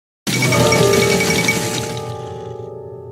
hoi4 news event notification Meme Sound Effect
hoi4 news event notification.mp3